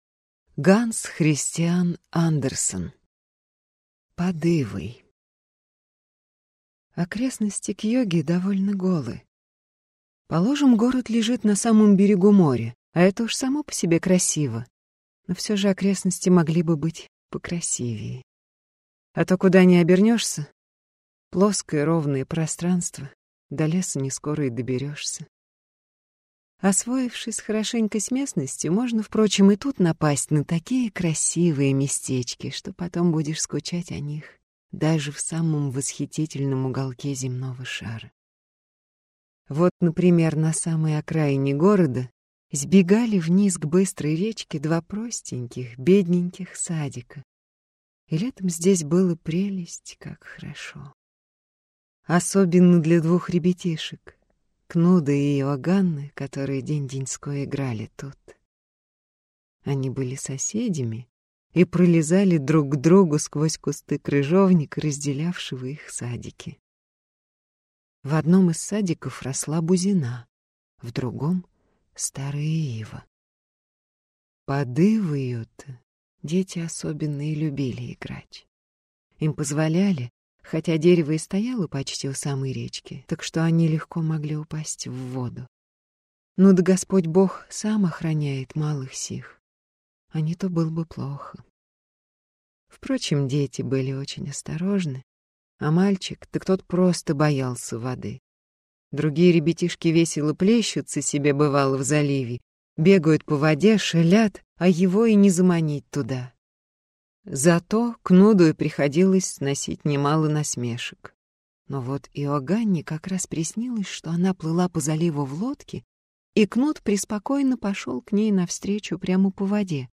Аудиокнига Под ивой | Библиотека аудиокниг